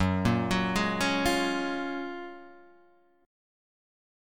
F#M9 Chord